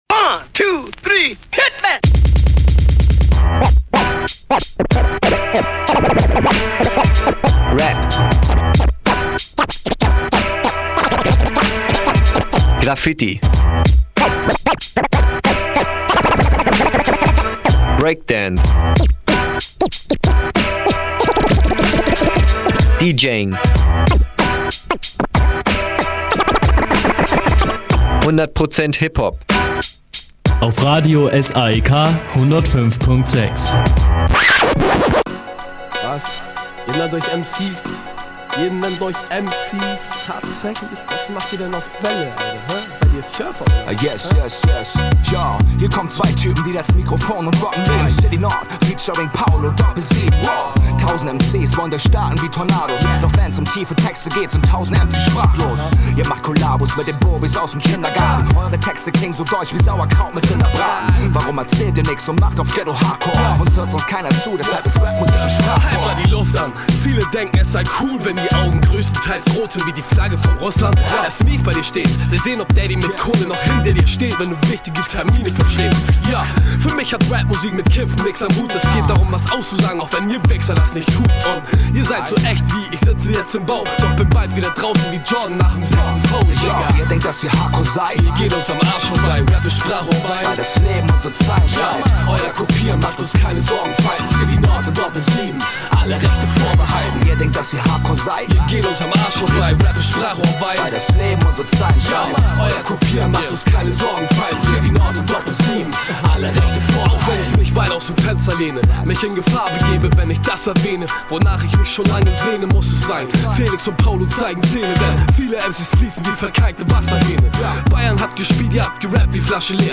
Inhalte: Pensive Special mit Interview, Tracks und Live Performance im Studio, VA Tips, Musik